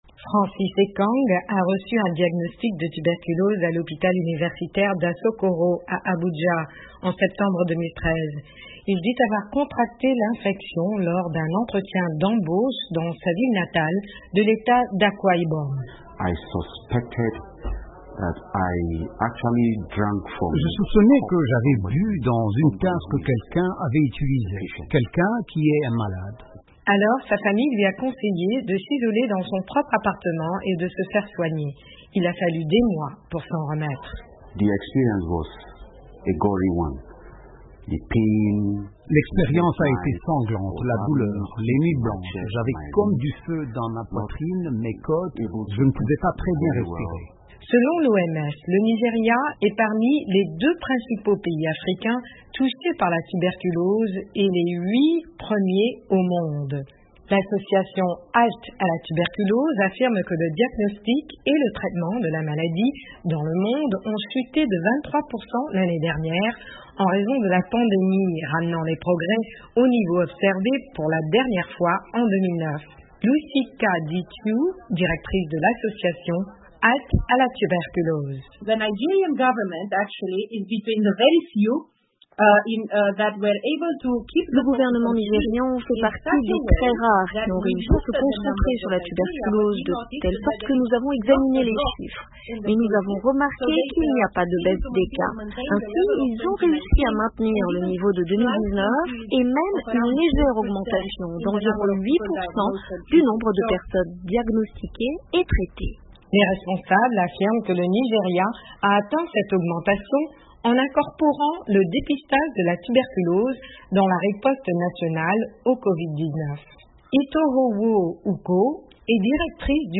Au Nigeria, le coronavirus provoque des dommages collatéraux sur la maladie bactérienne qui affecte les poumons. Les experts affirment que la pandémie a fait reculer de plusieurs années les progrès dans la lutte contre tuberculose. Un reportage